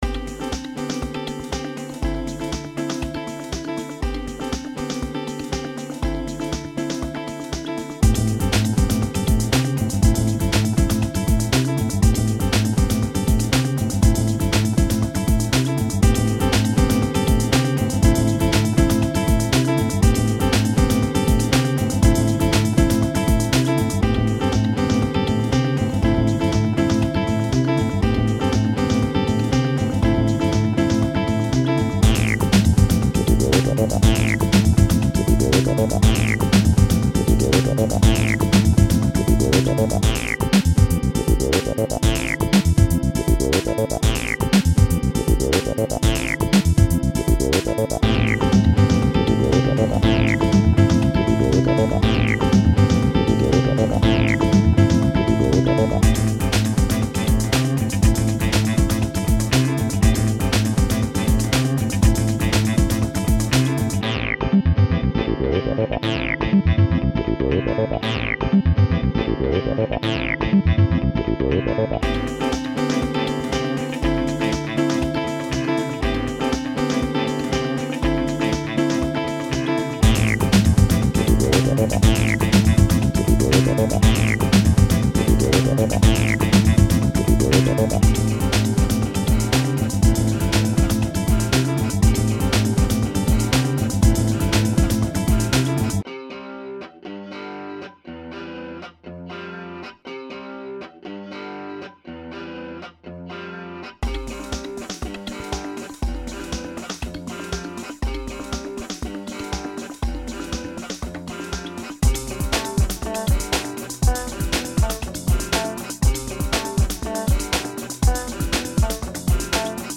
Just a crazy mix. Made with Acid Music.